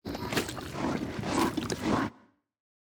PixelPerfectionCE/assets/minecraft/sounds/mob/guardian/guardian_idle2.ogg at mc116
guardian_idle2.ogg